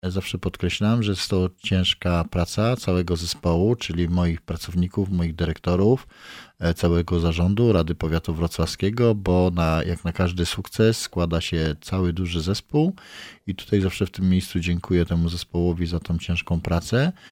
– Żadnemu samorządowi nie udało się otrzymać, trzy razy z rzędu statuetki – mówi Roman Potocki – Starosta Powiatu Wrocławskiego.